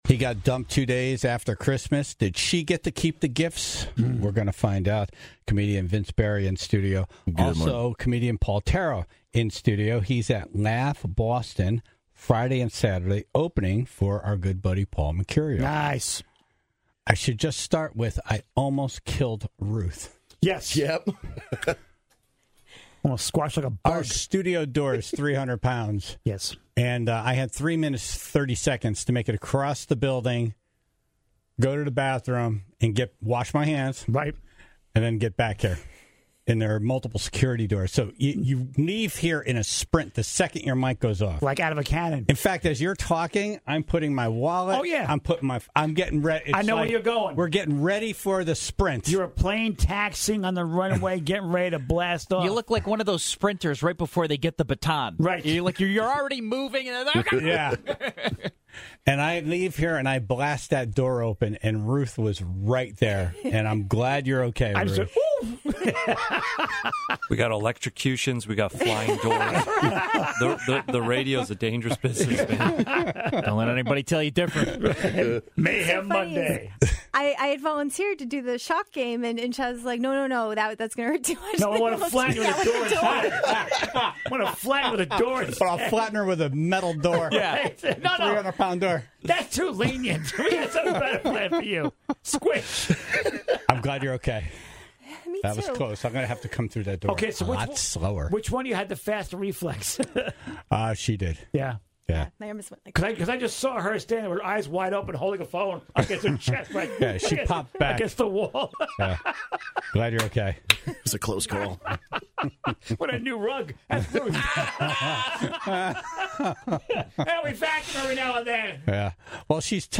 in studio this morning